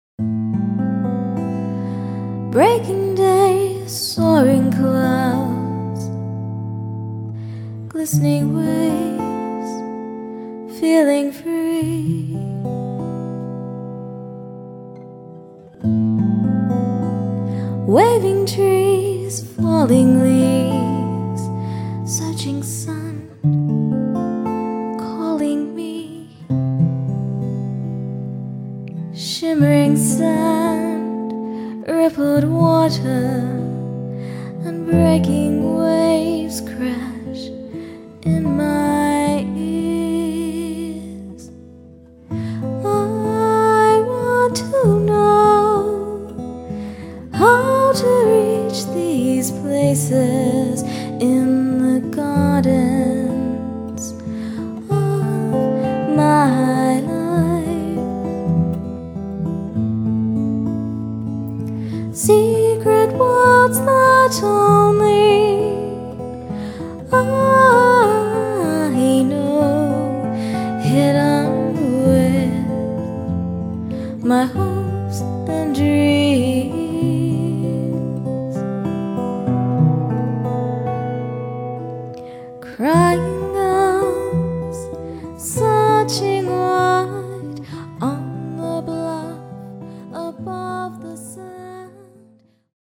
guitar-vocal duo